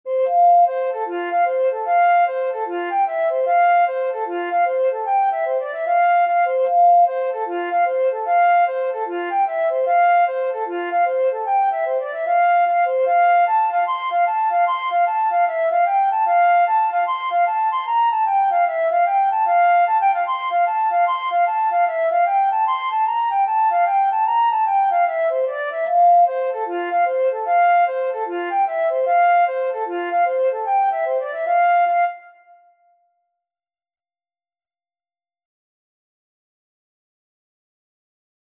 4/4 (View more 4/4 Music)
F5-C7
Flute  (View more Intermediate Flute Music)